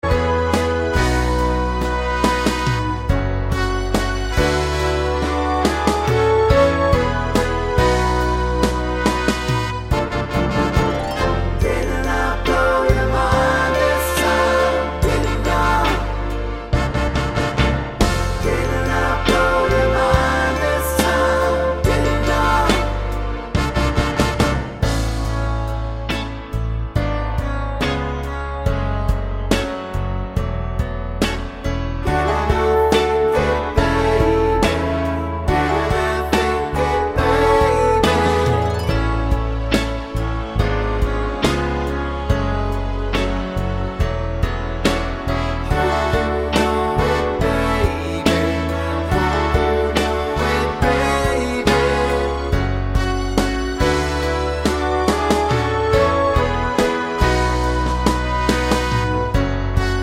no Backing Vocals Soul / Motown 3:28 Buy £1.50